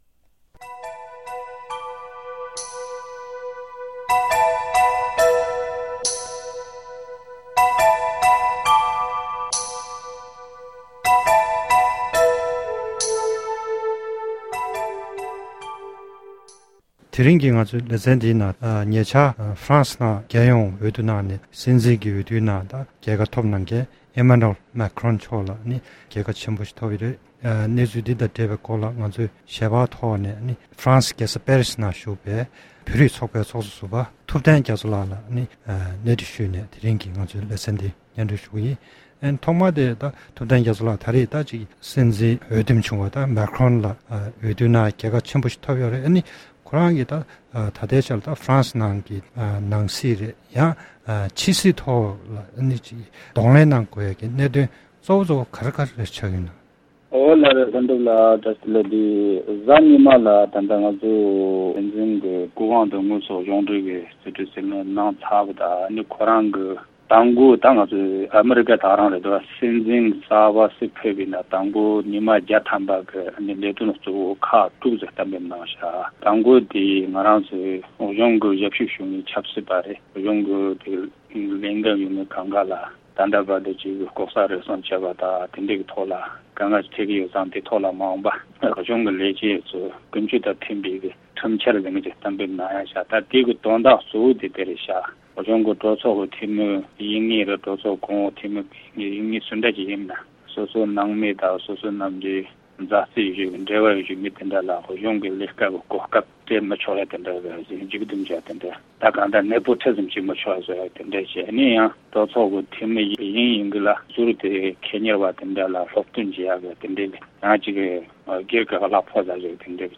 གནས་འདྲི་